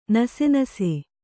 ♪ nase nase